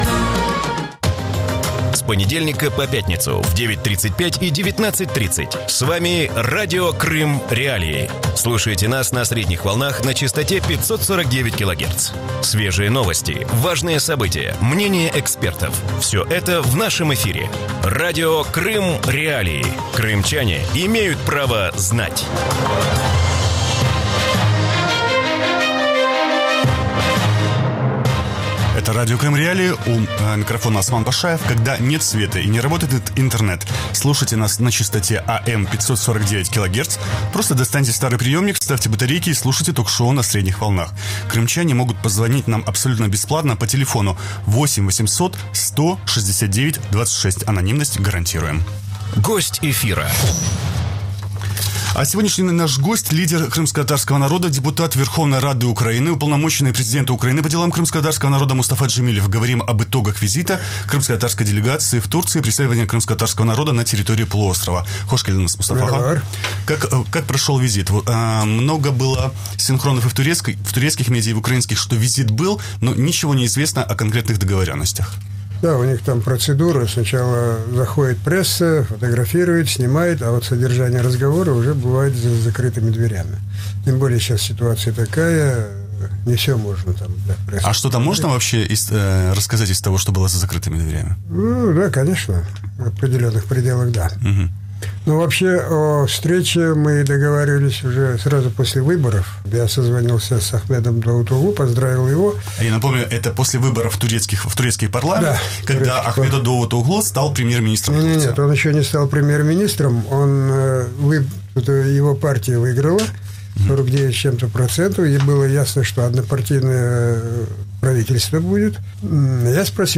Мустафа Джемилев гость утреннего эфира Радио Крым.Реалии